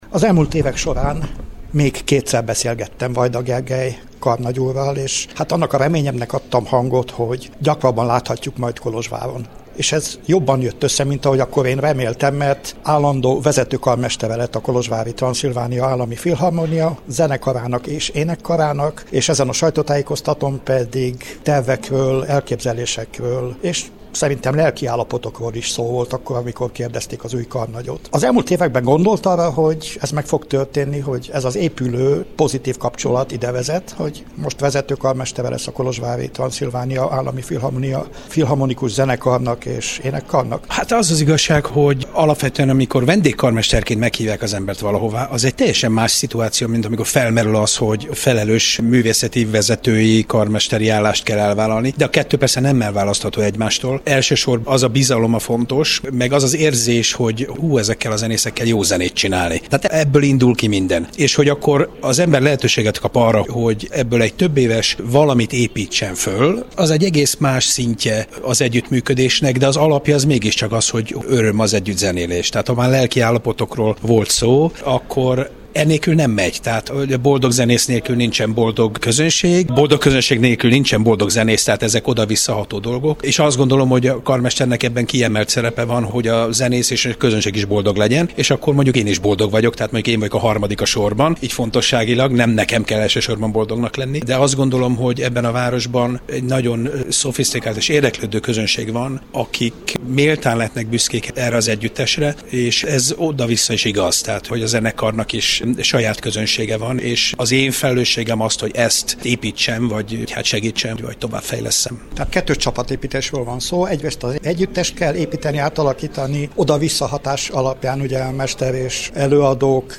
Vajda Gergely rádiónknak is ismertette elképzeléseit.
A karnagy ismertette az együttműködéssel kapcsolatos gondolatait és terveit, amelyekkel kapcsolatban az eseményt követően a Kolozsvári Rádiónak is nyilatkozott.